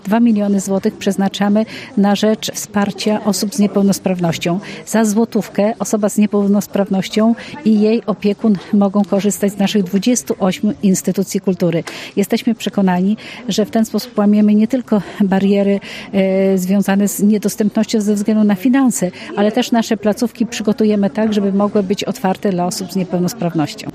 To właściwe rozwiązanie dla opiekunów i osób z niepełnosprawnościami podsumowuje Elżbieta Lanc, członkini zarządu województwa mazowieckiego: